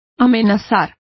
Complete with pronunciation of the translation of threatens.